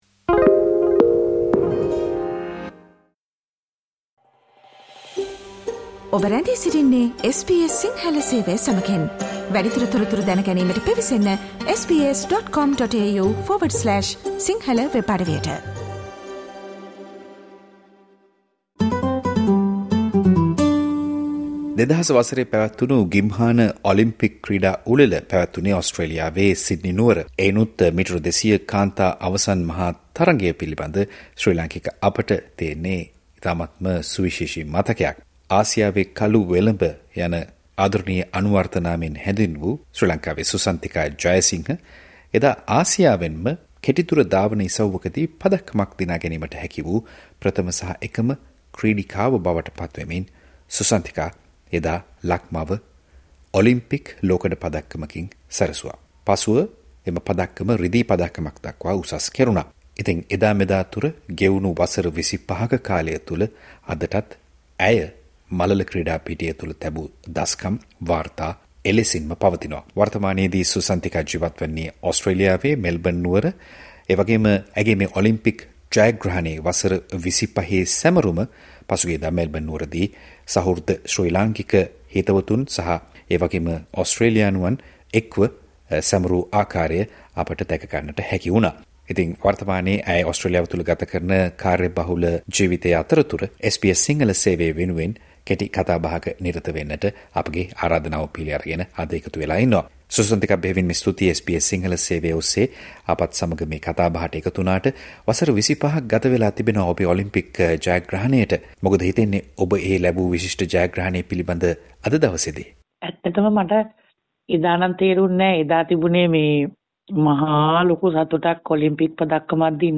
එදා මෙදා තුර කෙටි දුර ධාවන ඉසව්වක දී ලෝක ශූරතා හෝ ඔලිම්පික් මට්ටමේ පදක්කමක් දිනූ එකම ආසියාතික ක්‍රීඩිකාව වන සුසන්තිකා ජයසිංහ SBS සිංහල සේවය සමග කල කතාබහ.